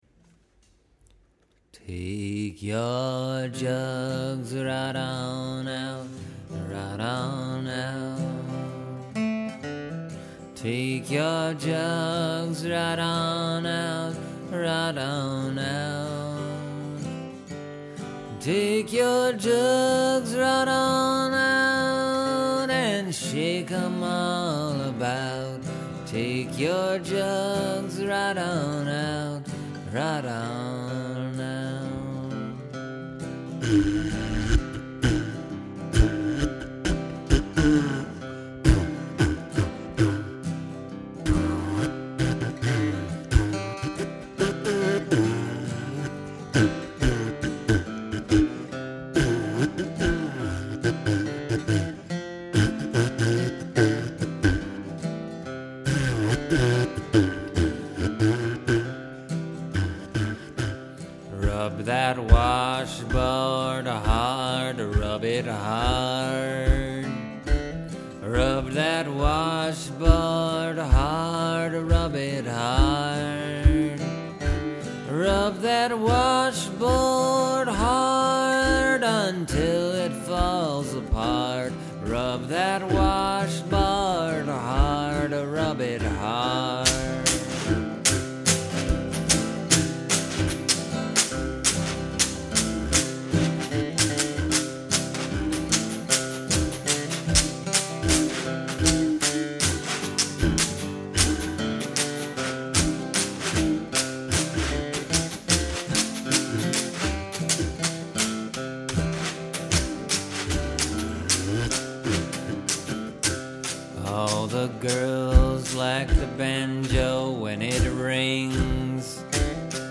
Then I recorded each part and layered them on top.
The jug is actually a beer bottle that I was drinking out of.
The washboard is an old washboard. I really start to pick up speed and technique near the “fiddle” section.
I’m just strumming the banjo as hard as I can. I didn’t even attempt fiddle or trombone, although I could have substituted a slide whistle for the trombone.